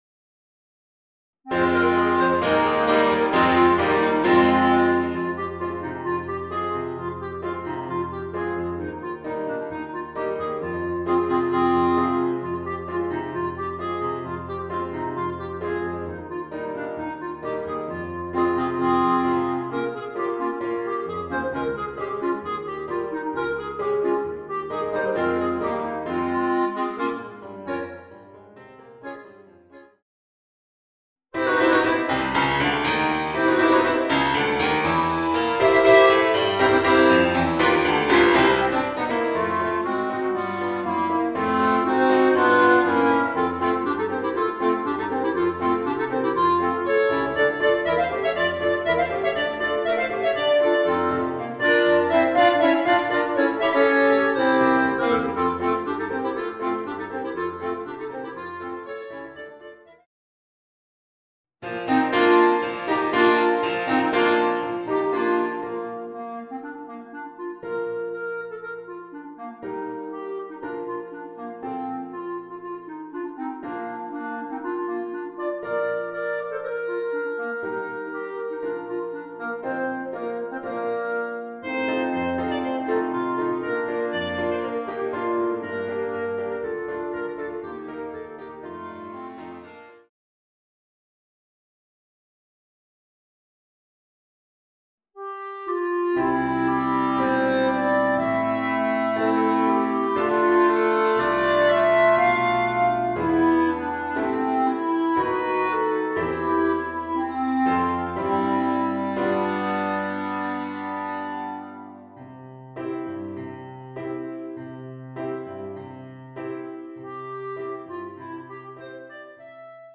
Voicing: 4 Clarinets and Piano